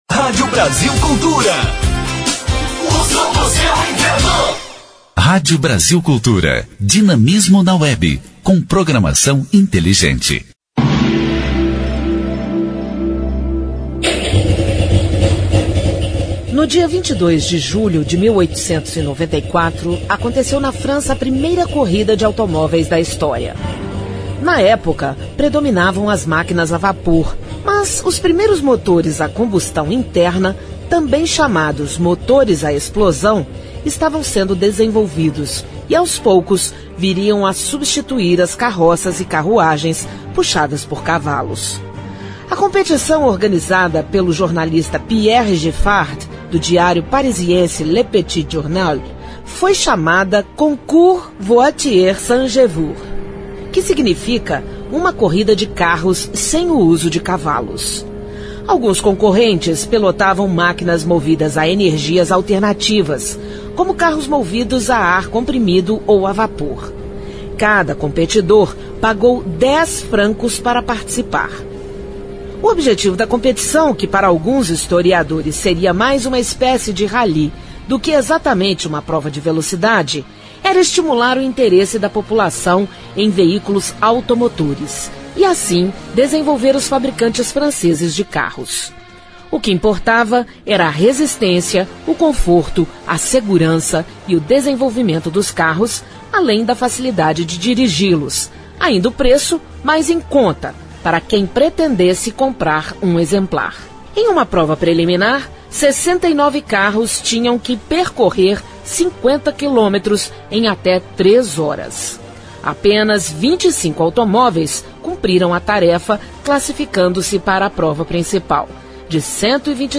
História Hoje : Programete sobre fatos históricos relacionados às datas do calendário. Vai ao ar pela Rádio Brasil Cultura de segunda a sexta-feira.